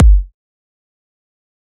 EDM Kick 3.wav